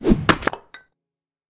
axe.ogg.mp3